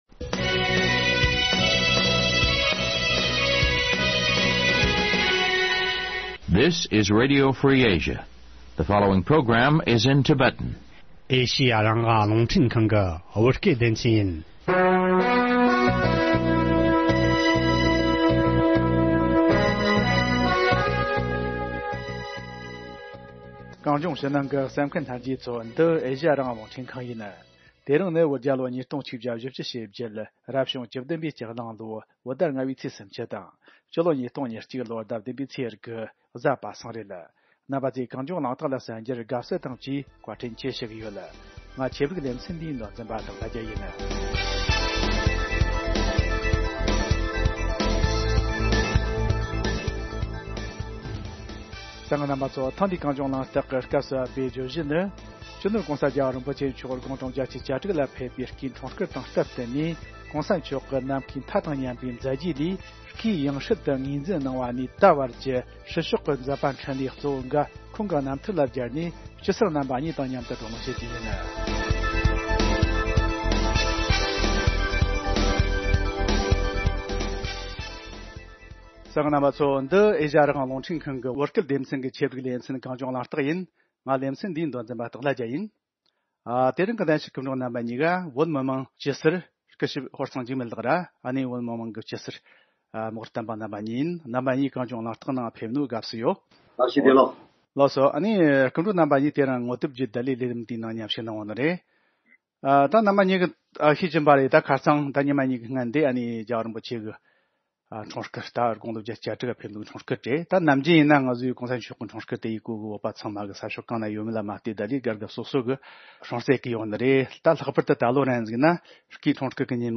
བགྲོ་གླེང་ཞུས་པ།